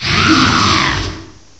Add all new cries
cry_not_sandygast.aif